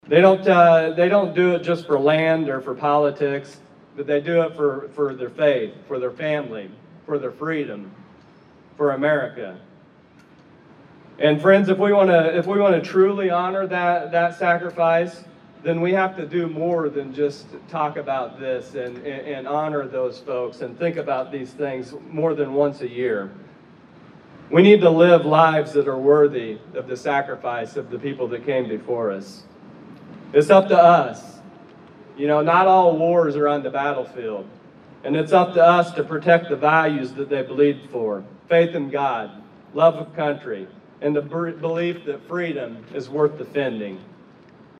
It was the First Anniversary of the downtown Vandalia Veteran’s Memorial Park, and it was well represented with a ceremony and then a Veteran’s Day parade through downtown Vandalia on Saturday.
State Representative Blaine Wilhour talked about the need to honor our Veteran’s Day.